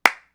Claps
CLAP.1.NEPT.wav